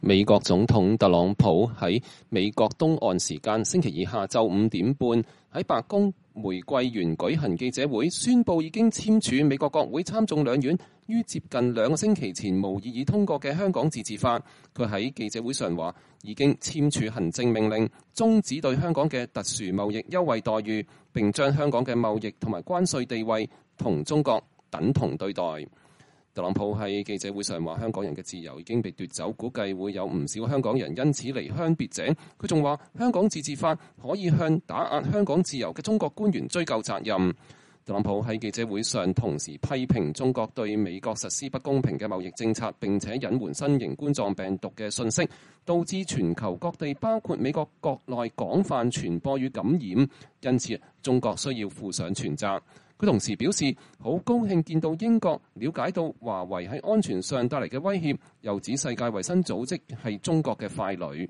特朗普總統在美東時間週二下午5時半在白宮玫瑰園召開記者會